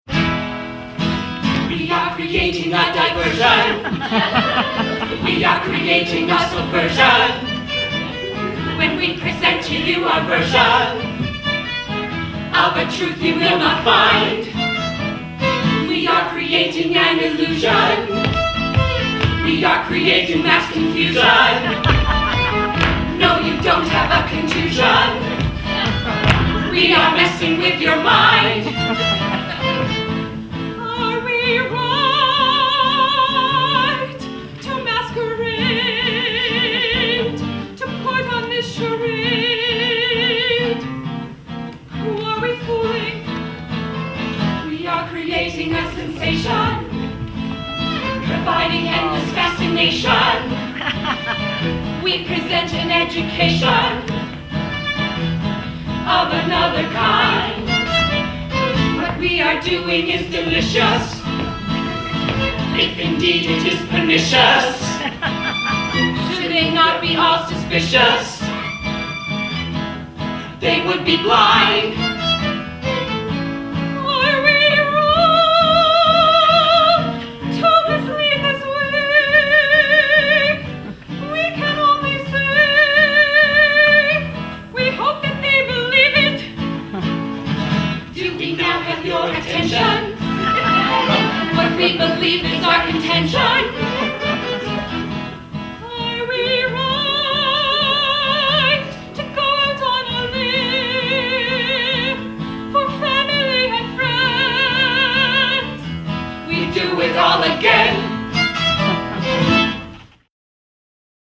The new ‘roots’ musical about community and family.